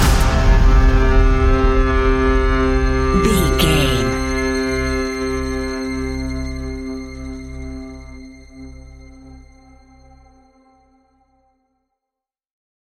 Epic / Action
Fast paced
In-crescendo
Thriller
Ionian/Major
industrial
dark ambient
EBM
synths
Krautrock